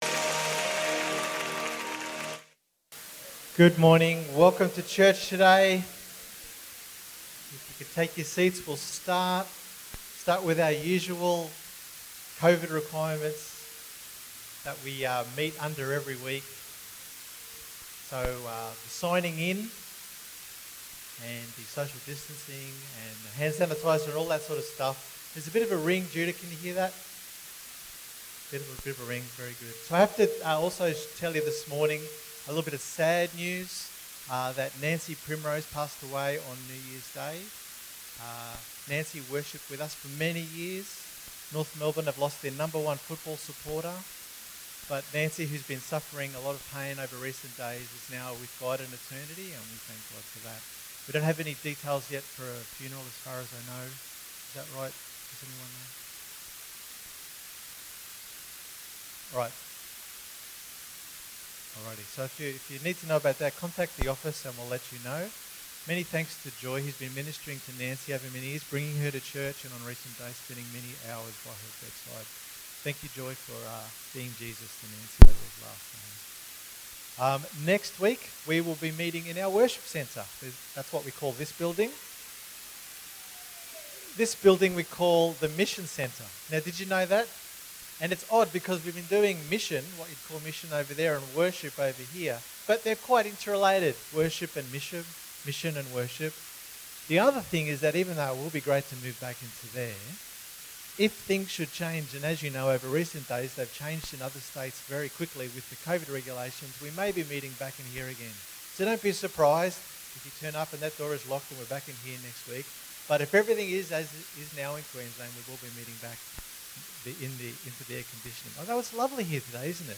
Sunday_Meeting_3rd_January_2021_Audio.mp3